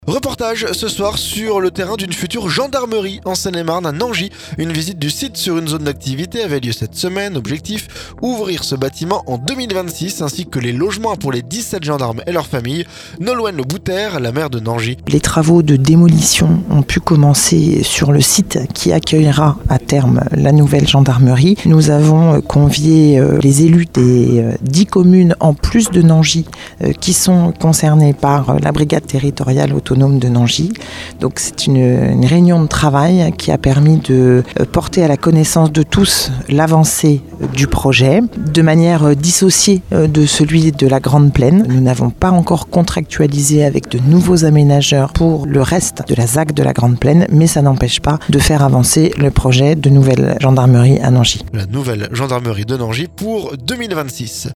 Reportage ce soir sur le terrain d'une future gendarmerie en Seine-et-Marne...
Nolwenn Le Bouter, la maire de Nangis.